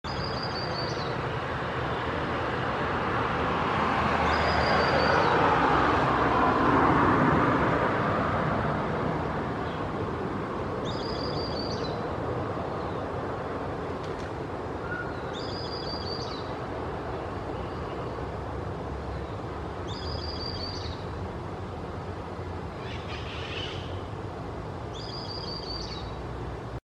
Southern Beardless Tyrannulet (Camptostoma obsoletum)
Life Stage: Adult
Location or protected area: Belgrano
Condition: Wild
Certainty: Observed, Recorded vocal